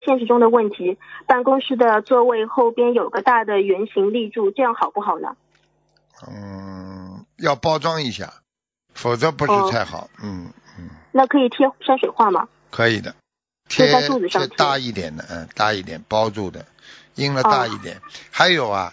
目录：☞ 2019年10月_剪辑电台节目录音_集锦